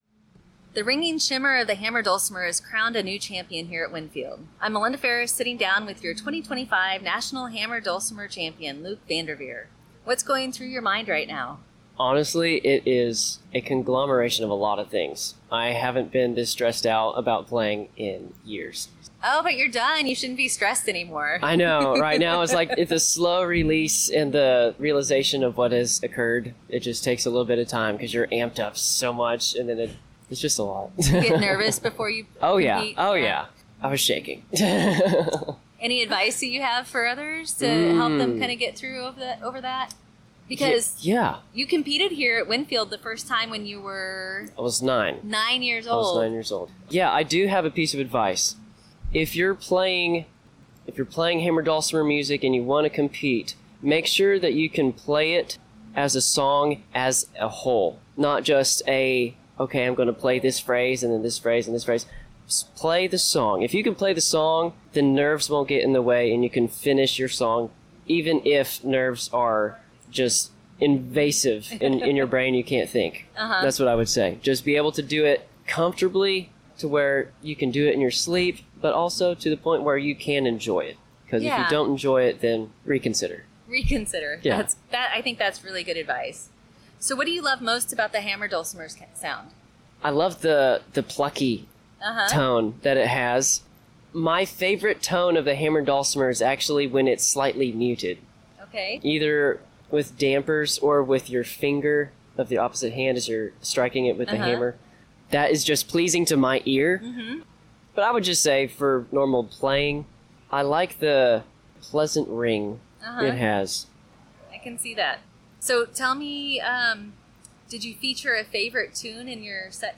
Campground Radio interview.